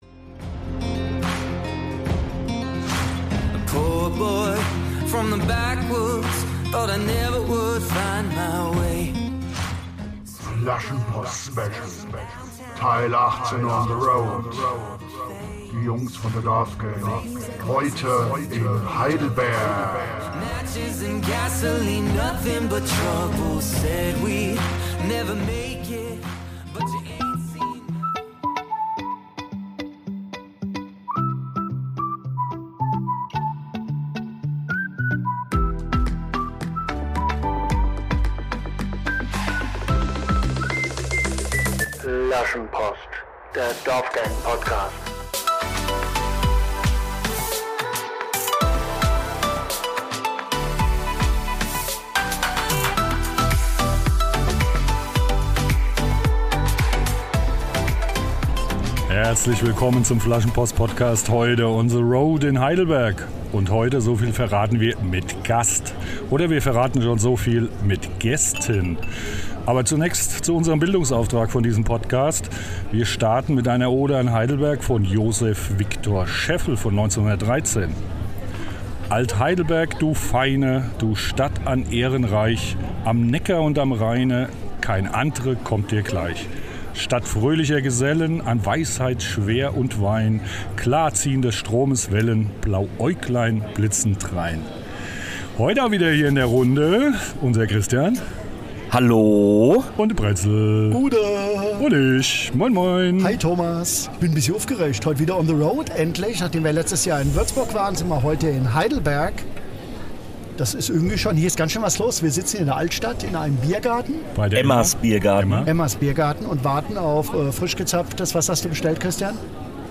Diesmal sind wir in Heidelberg unterwegs.
Weiter ging es auf ein Live Schnäsping auf der alten Brücke sowie in die Kneipe "Betreutes Trinken" in der unteren Stadt. Unterwegs machten wir noch kurzen Halt in der wunderbaren alten Brennerei (Shownotes) und wähnten uns im Paradies.